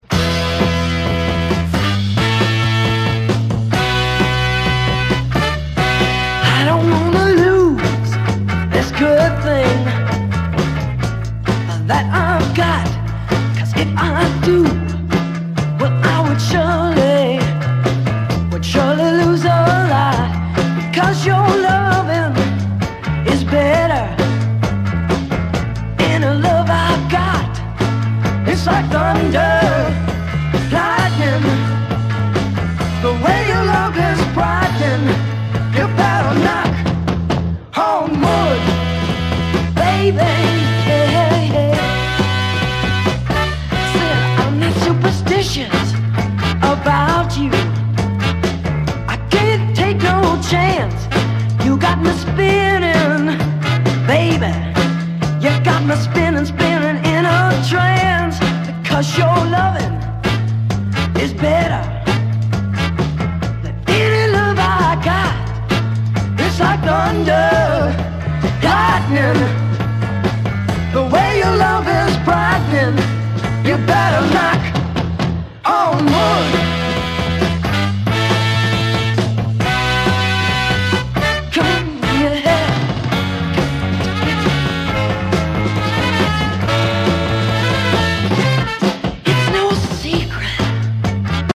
Gritty R&B garage version